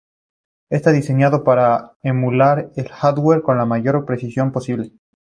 Pronúnciase como (IPA) /ˈxaɾdweɾ/